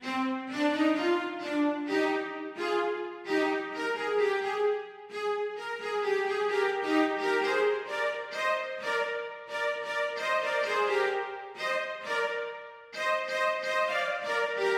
小提琴的发展
描述：这是一个不能用在我正在创作的新管弦乐上的旋律。
Tag: 130 bpm Orchestral Loops Violin Loops 2.49 MB wav Key : Unknown